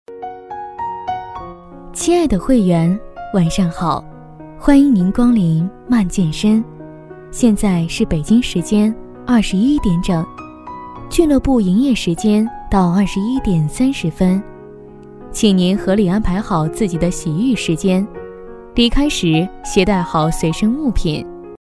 女285-语言IC-【温馨提醒】
女285-明亮柔和 甜美亲切